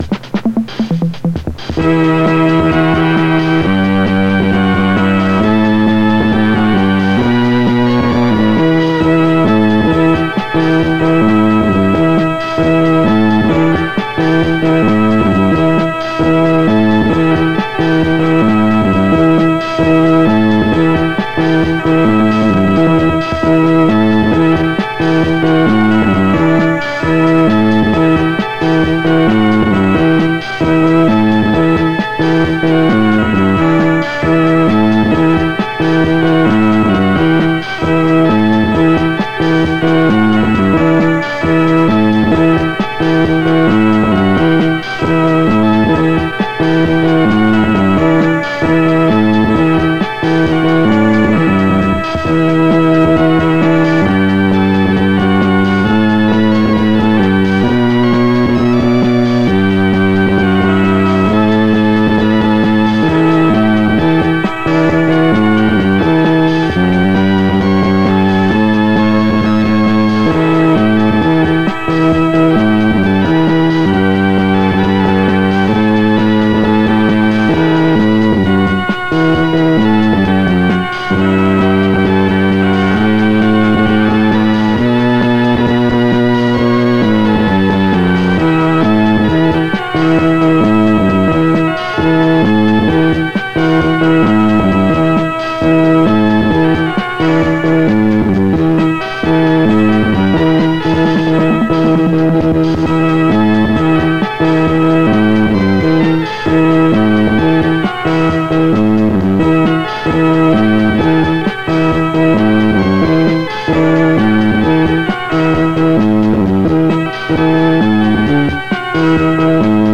experimental/psychedelic/Space Funk Synthesizer sound